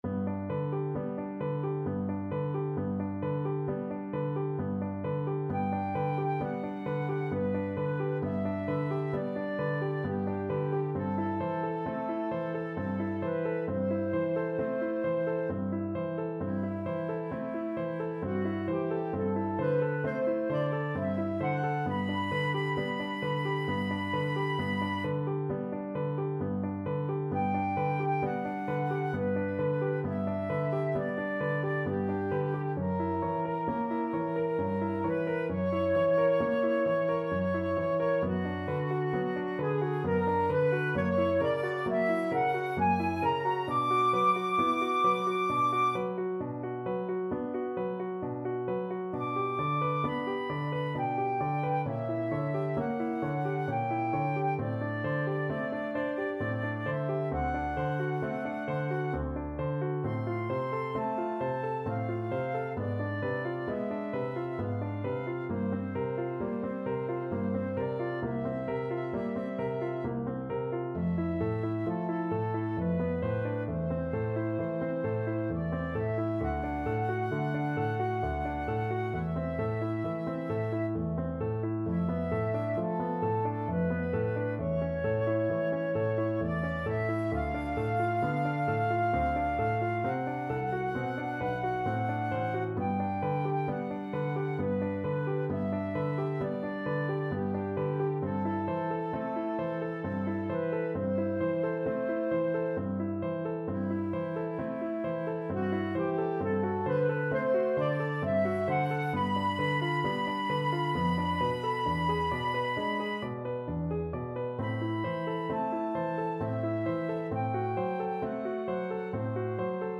flute and piano